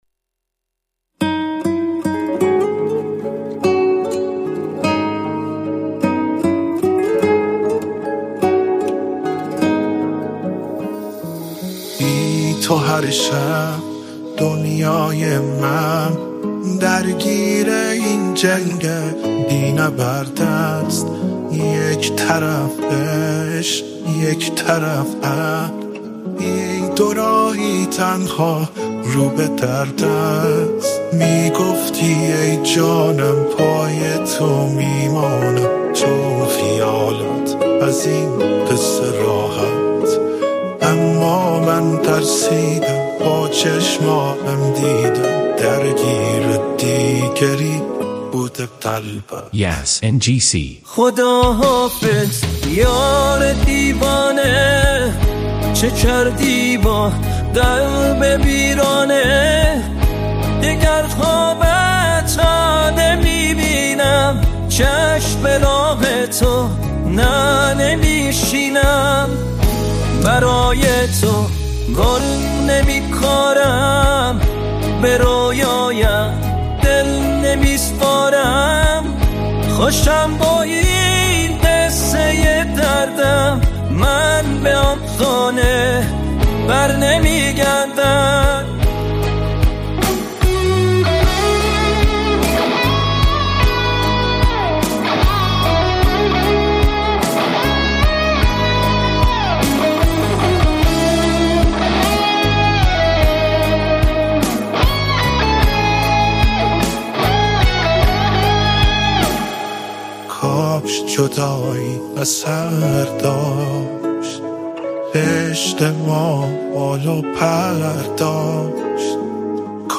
دانلود آهنگ ریمیکس